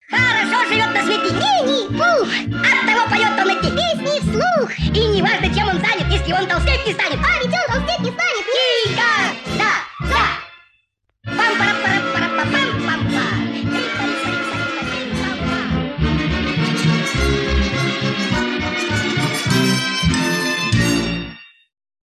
песня из мультфильма